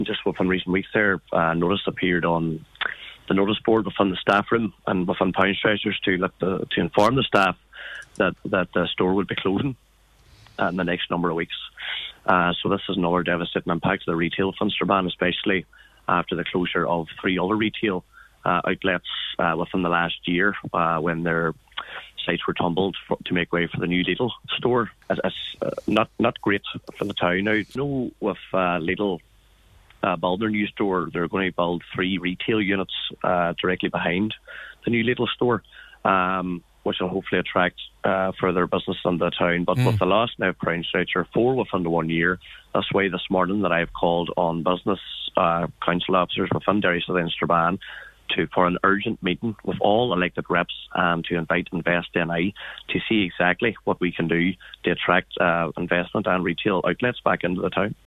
Councillor Jason Barr who is Deputy Mayor of Derry City and Strabane District Council is calling on Invest NI to urgently intervene: